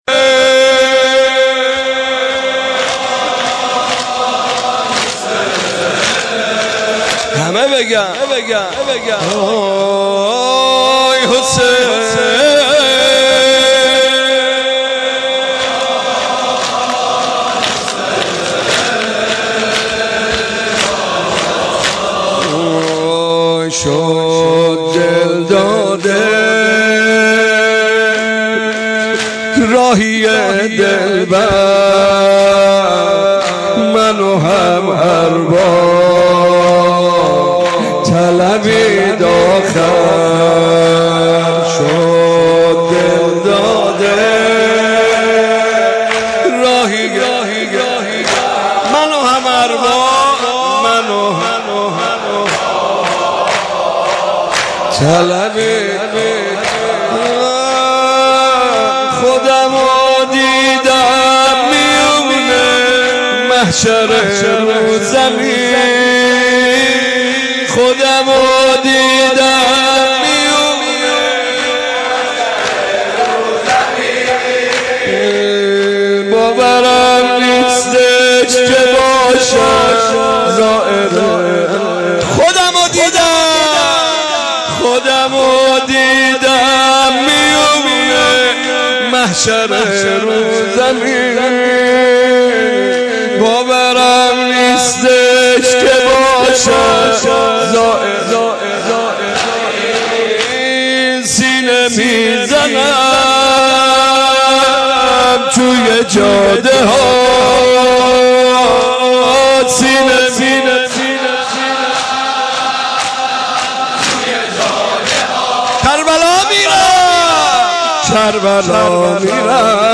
زمینه اربعین